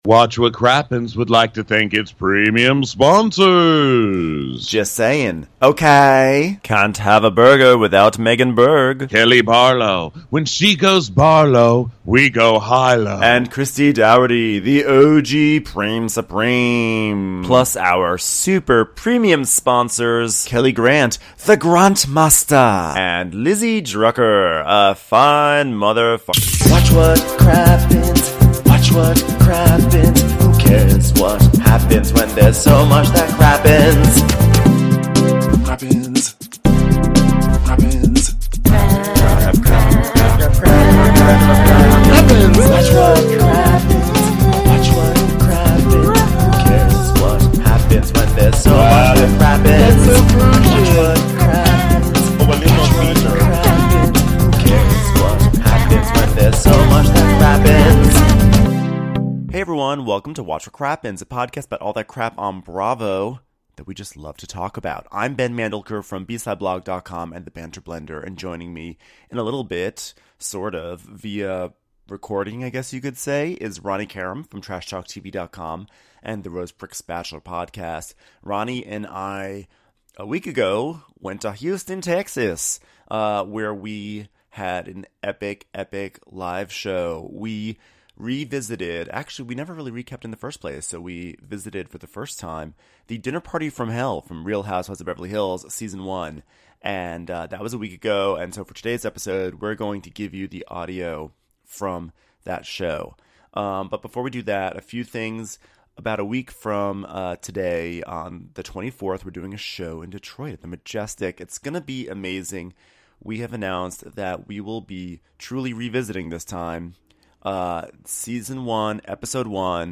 #662 RHOBH: Dinner Party From Hell! (Live from Houston)